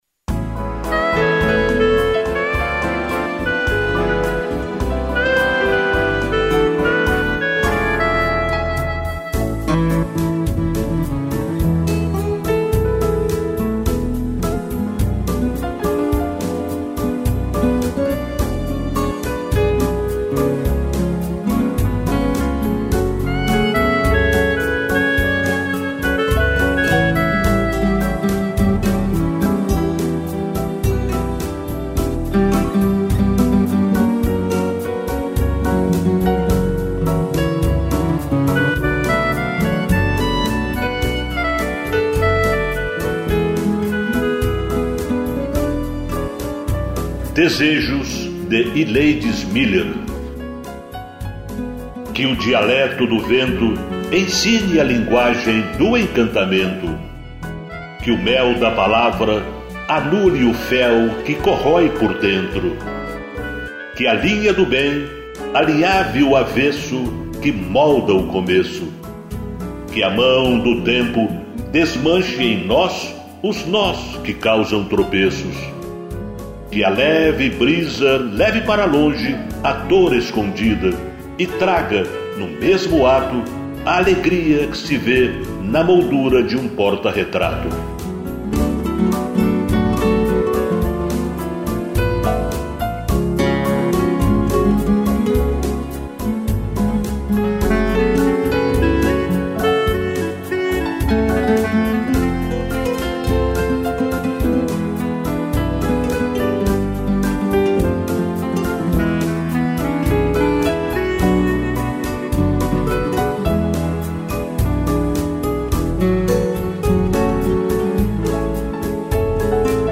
piano e clarinete